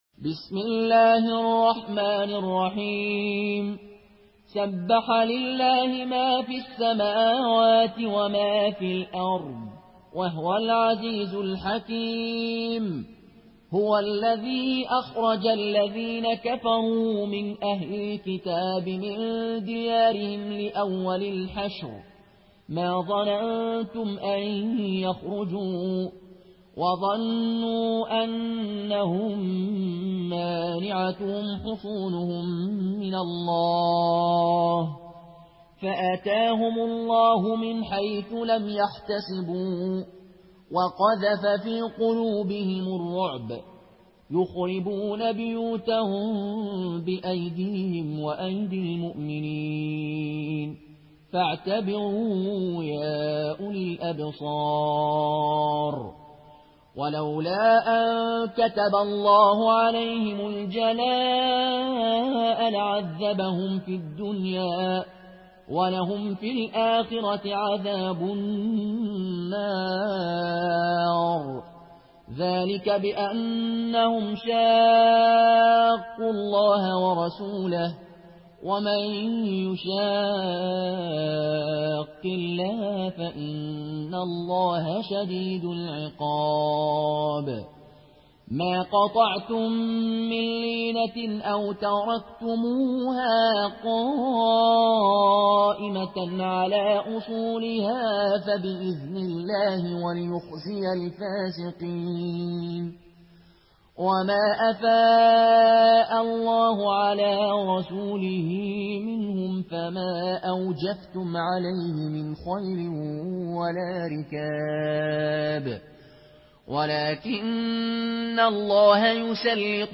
Une récitation touchante et belle des versets coraniques par la narration Qaloon An Nafi.
Murattal